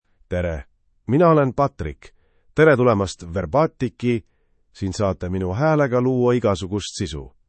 MaleEstonian (Estonia)
Patrick is a male AI voice for Estonian (Estonia).
Voice sample
Patrick delivers clear pronunciation with authentic Estonia Estonian intonation, making your content sound professionally produced.